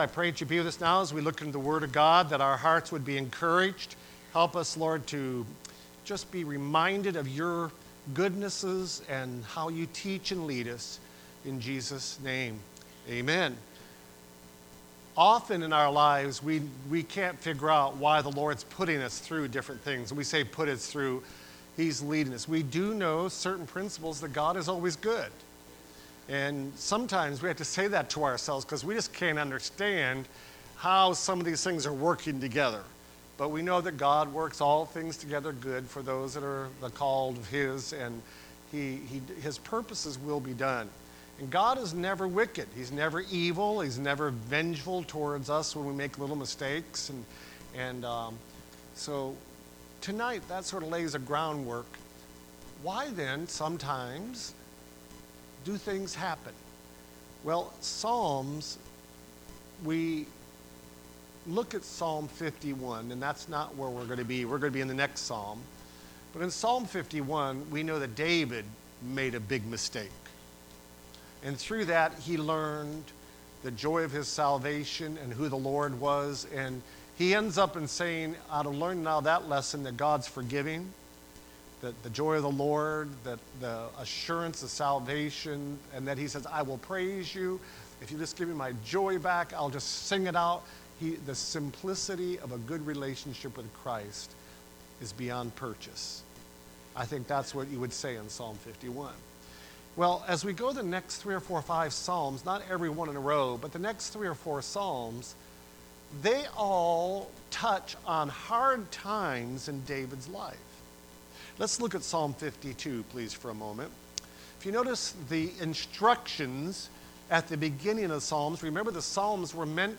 Service Type: Wednesday Prayer Service